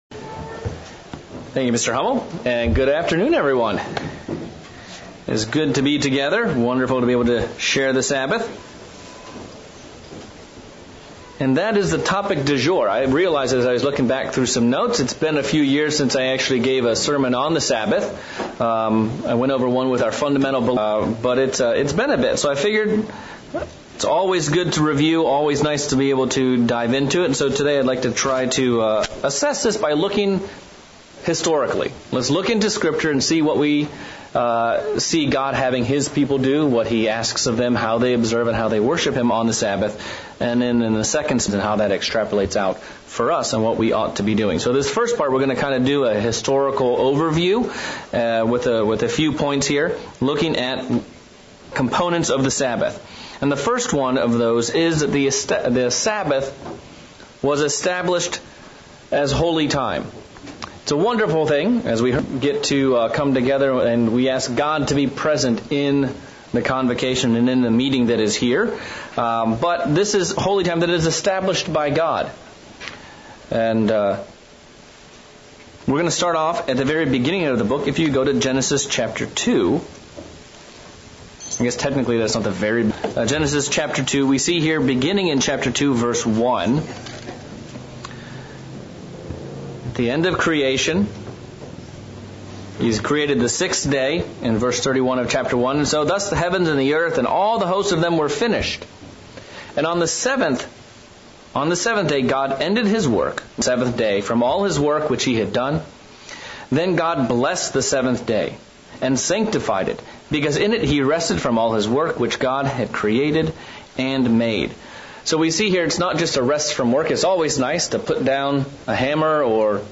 Part 1 of a 2 part sermon on the Sabbath. This message looks at the Sabbath from the historical perspective and what are some of the things God wants us to do regarding his Sabbath day? What path did Israel follow with what God gave them to keep and observe?